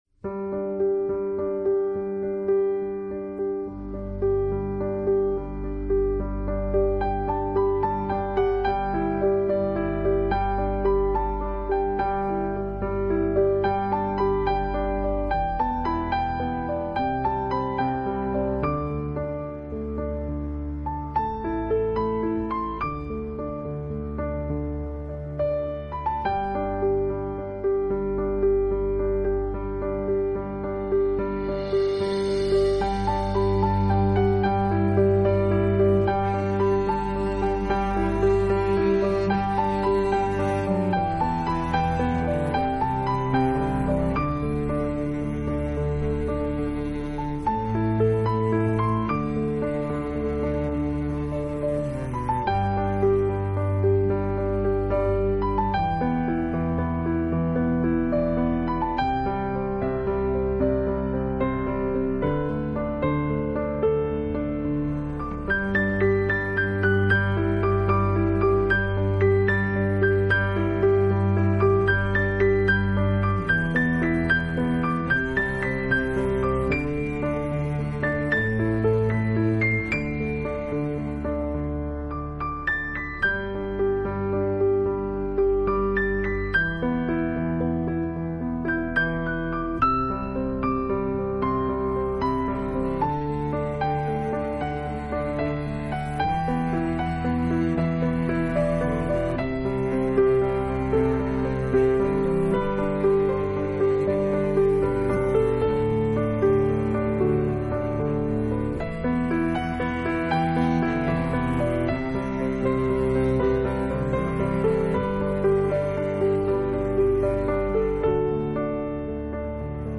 风格流派: new age